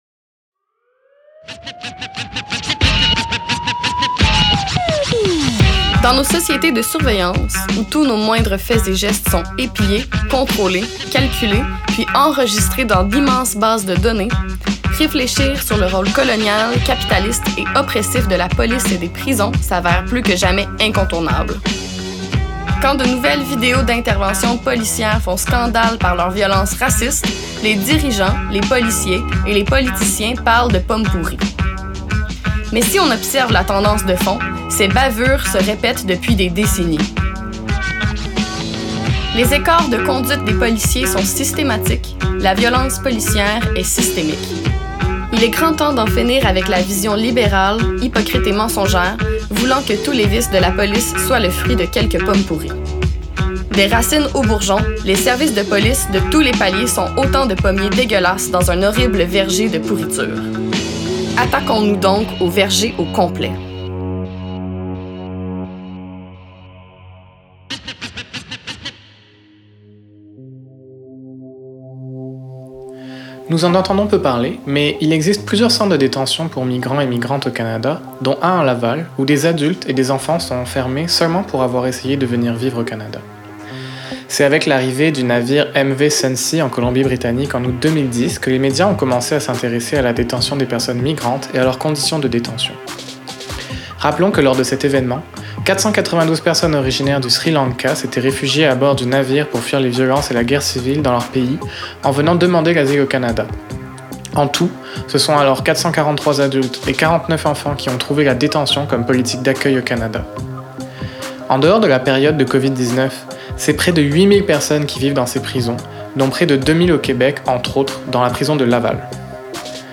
Saison 2, épisode 4: La prison pour migrant·e·s - Entrevue avec SSF | CLAC-Montréal